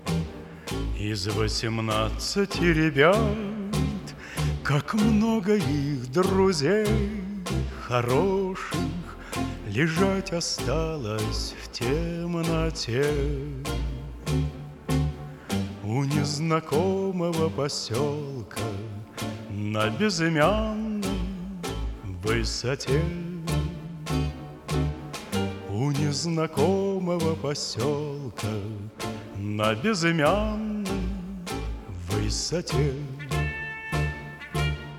Жанр: Рок / Русские